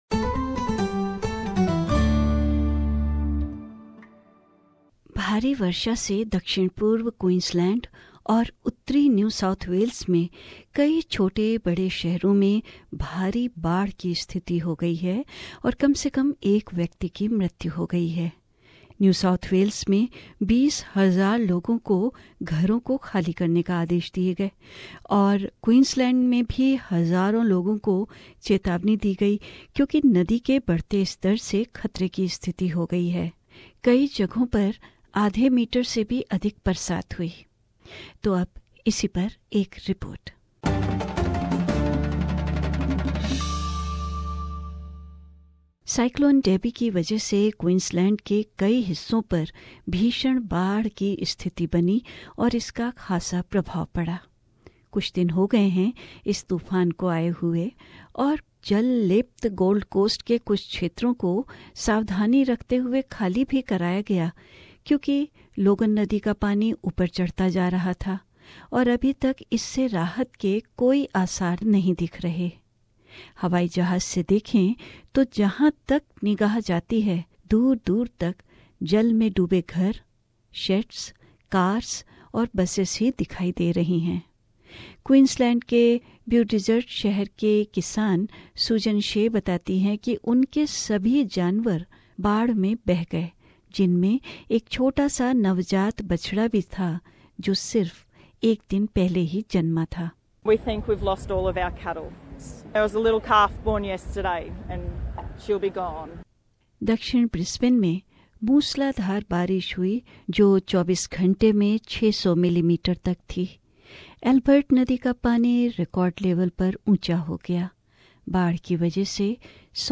Torrential rain has flooded towns and cities in south east Queensland and northern New South Wales resulting in the death of at least one person. A report